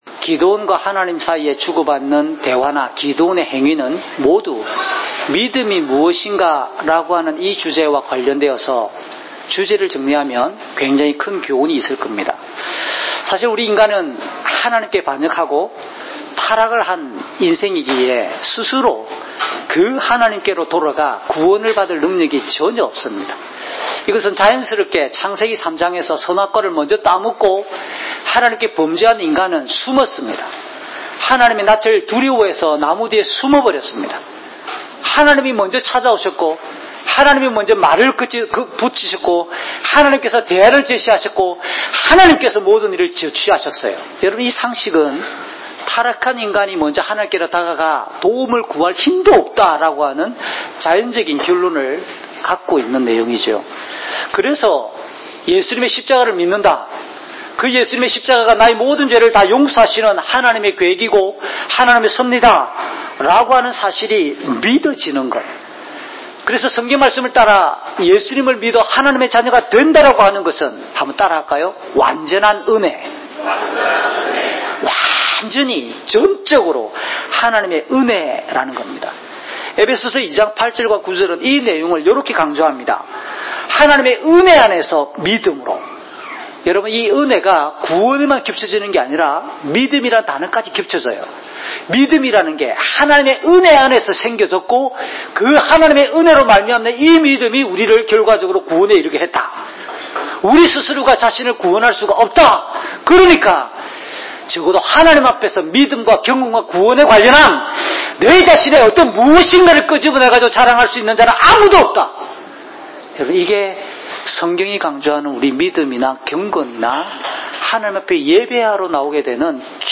주일 목사님 설교를 올립니다.